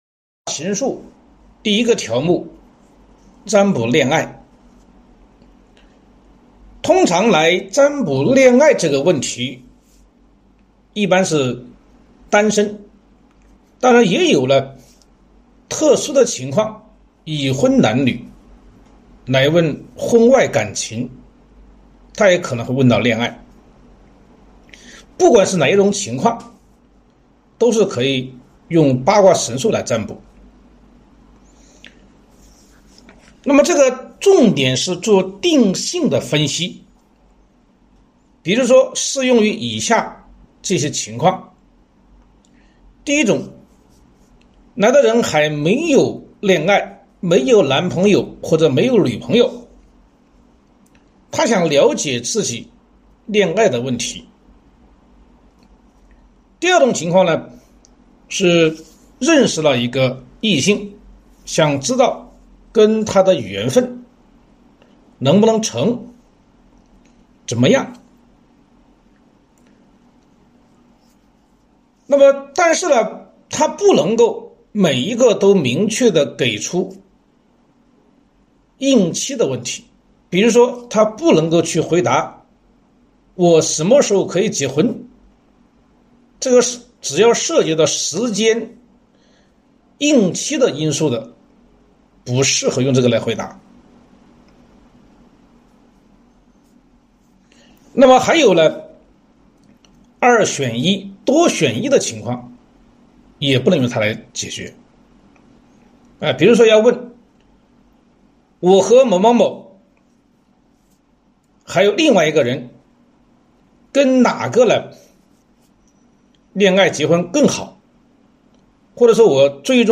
录音普通话清晰。据说6个小时就能学会的占卜术，简短易学，准确度高，并且可以涉及生活中的方方面面，包含六十多种常见事项。